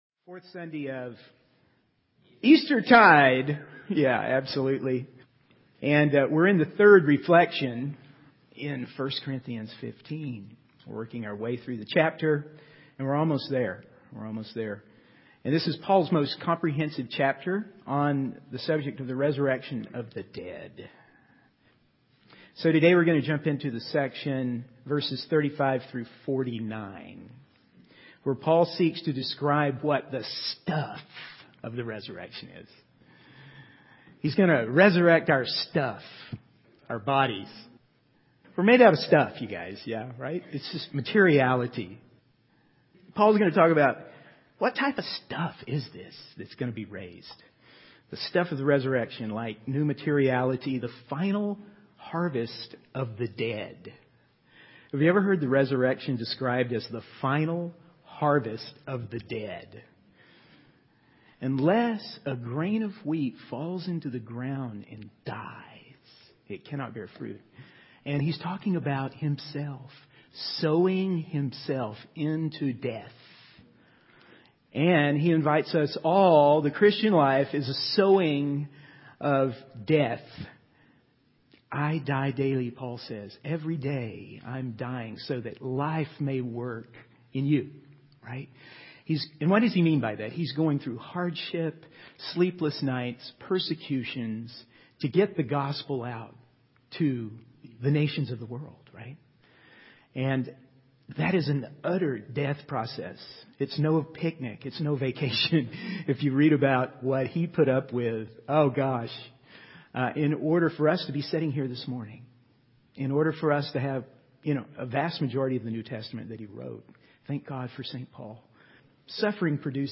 In this sermon, the preacher discusses the concept of hope in relation to the resurrection of the dead. He emphasizes that faith, hope, and love are essential, and that optimism is not the same as hope.